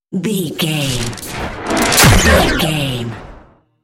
Whoosh to hit technology
Sound Effects
Atonal
dark
futuristic
high tech
intense
tension
woosh to hit
the trailer effect